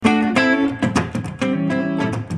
Free MP3 funk music guitars loops & sounds 1
Guitare loop - funk 24